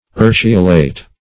Urceolate \Ur"ce*o*late\, a. [L. urceolus, dim. of urceus a